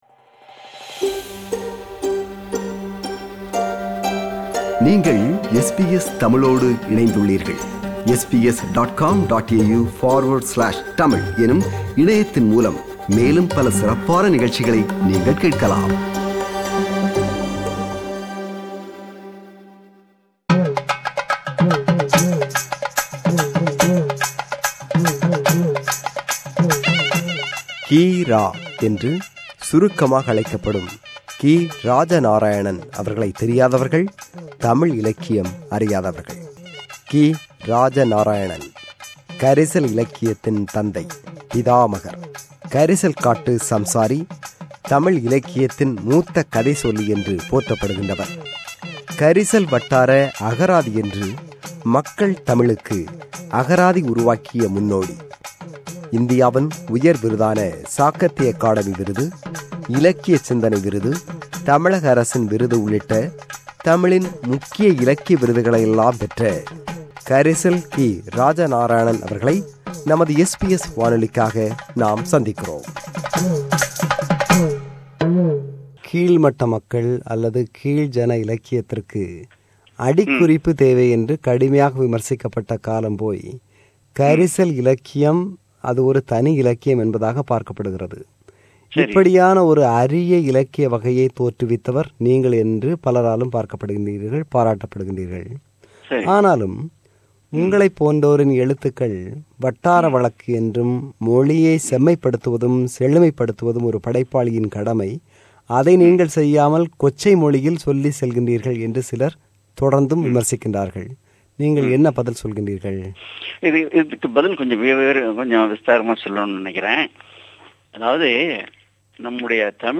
கி.ரா அவர்கள் 10 ஆண்டுகளுக்கு முன்பு (2011 ஆம் ஆண்டு) SBS - தமிழ் ஒலிபரப்புக்கு வழங்கிய நேர்முகத்தின் இரண்டாம் (நிறைவுப்) பாகம்.